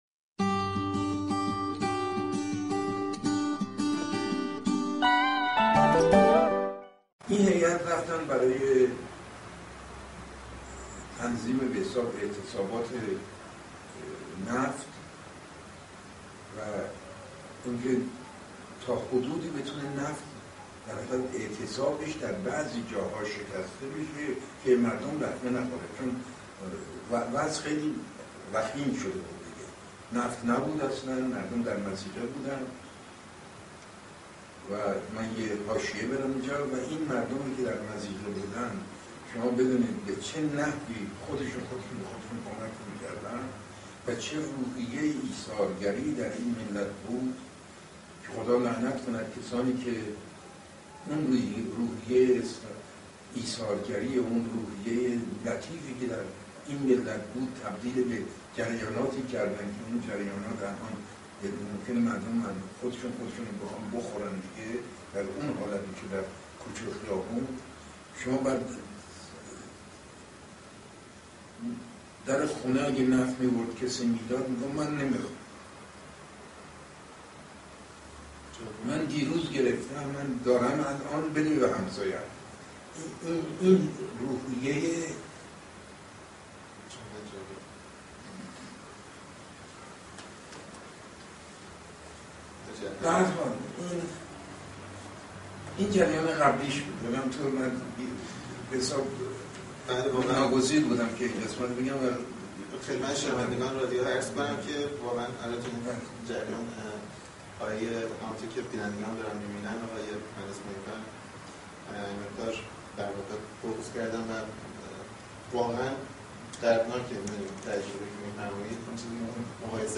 ویدئوکست مستند مصاحبه رادیو 808 با مهندس علی اکبر معین فر، پدر مهندسی زلزله ایران